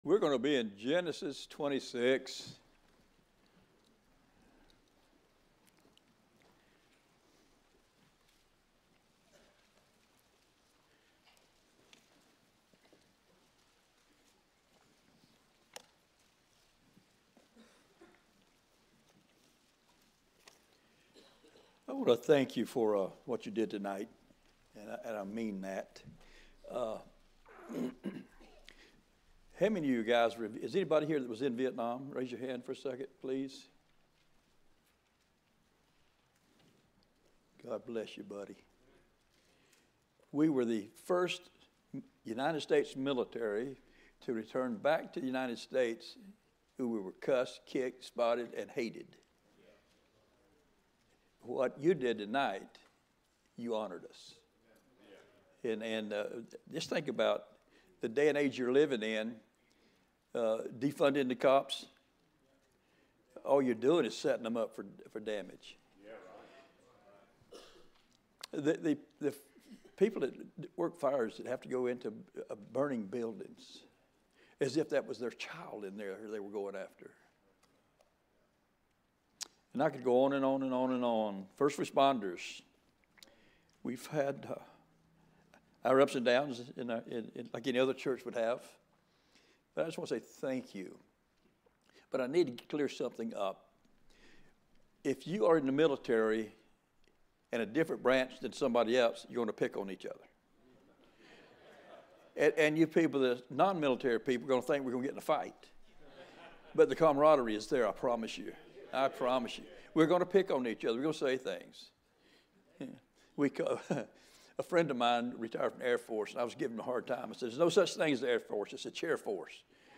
2021 Preacher's Delight Conference Sermons